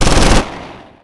（MP5冲锋枪声音）